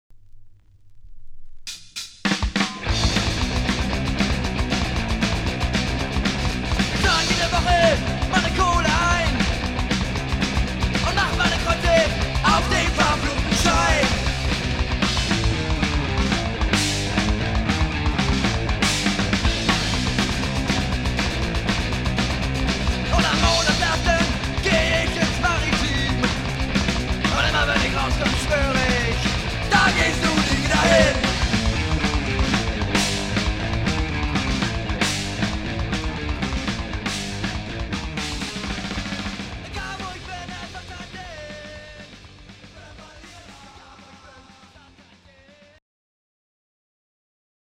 Das kurzlebige Trio
Rock’n’Roll-Punk